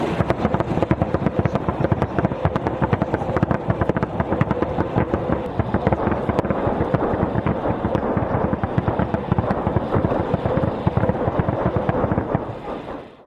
На этой странице представлены звуки работы РСЗО \
Мощь и разрушительная сила РСЗО Град